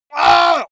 Gemafreie Sounds: Schreie